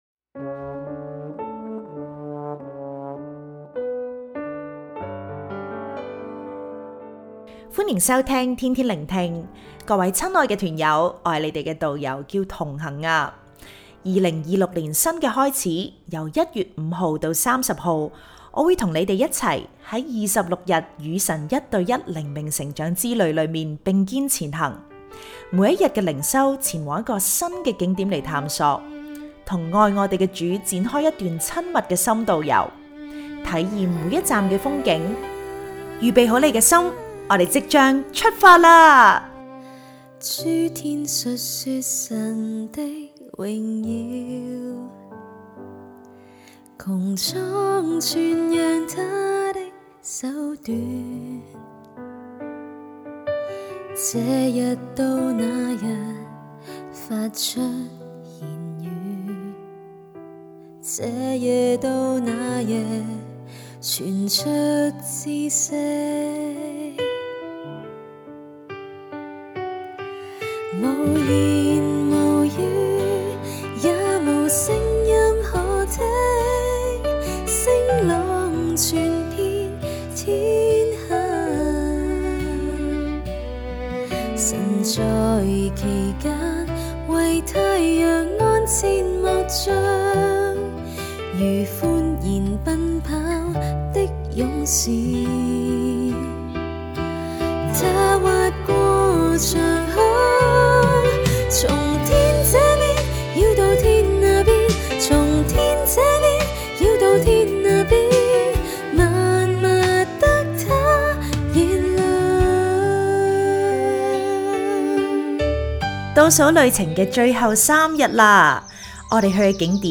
🎶靈修詩歌：《詩篇19》